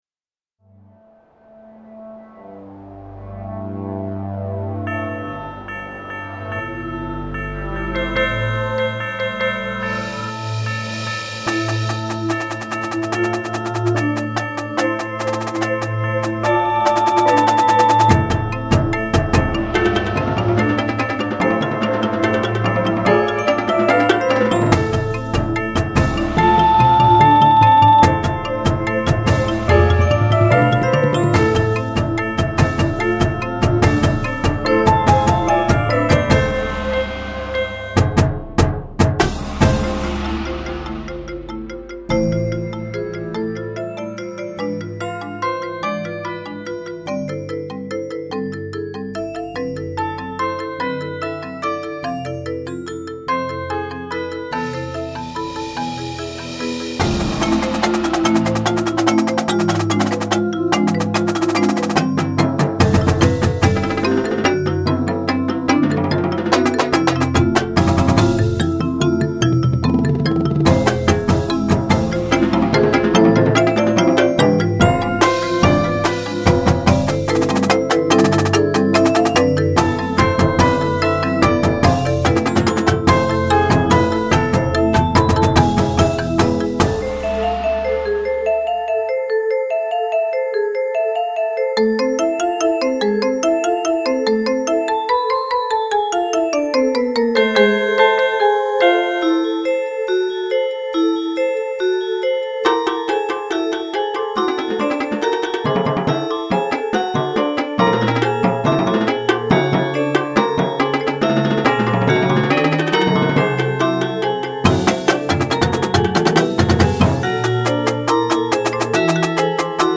Grade Level: Jr. High (For the Beginning Drum Line)
• 2-4 Marimbas (2 and Opt. 4 mallet)
• 2-3 Vibes
• 1 Xylophone
• 1 Bell/Glock
• 1 Chime
• 3 Synth/Piano
• 2-4 Aux Percussion
• Snare
• Quints
• 5 Bass Drums
• Marching Cymbals (Can be played by Aux Perc if needed)